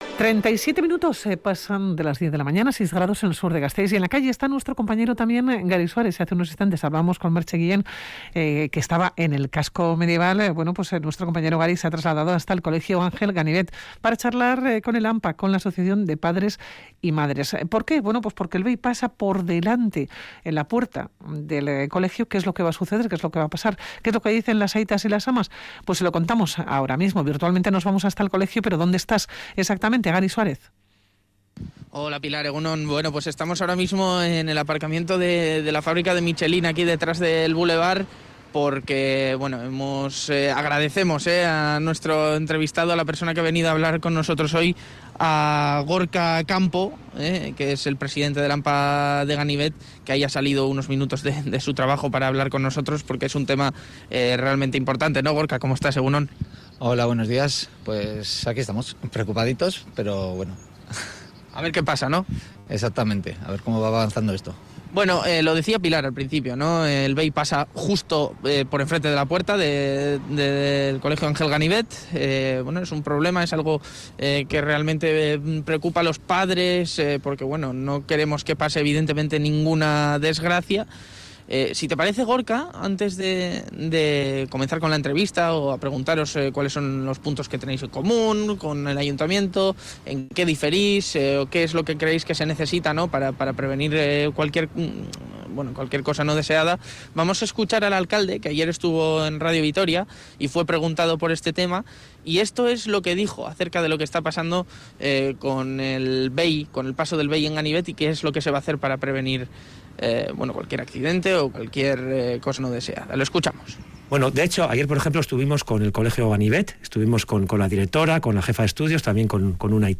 Radio Vitoria Gaur Magazine charlamos